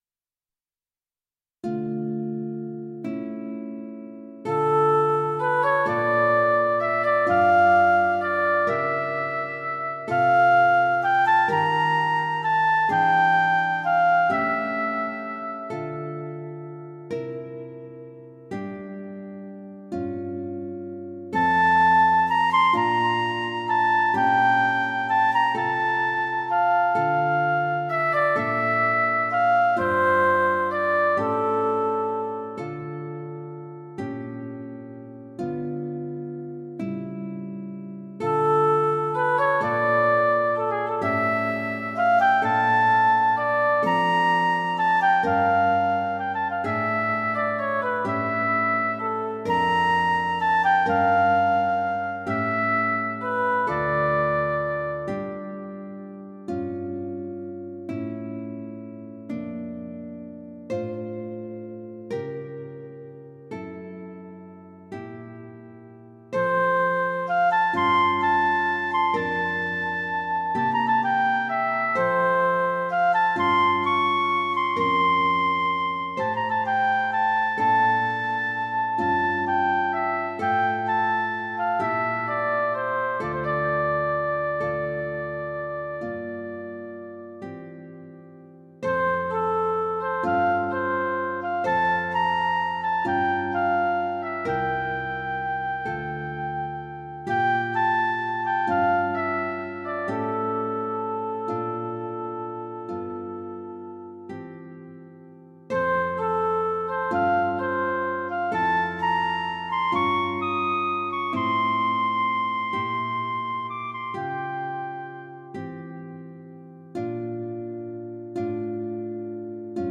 物憂い感じからほのかな光まで。フルートが奏でます。